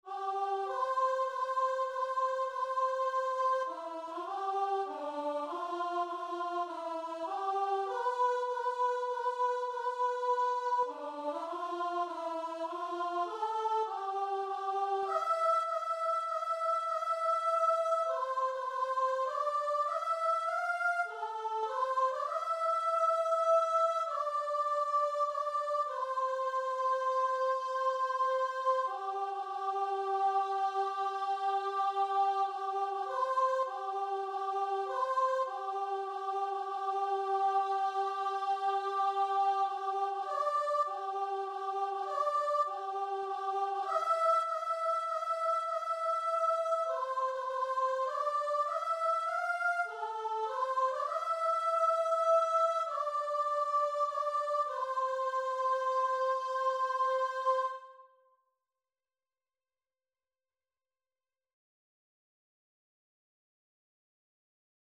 Christian
3/4 (View more 3/4 Music)
Classical (View more Classical Guitar and Vocal Music)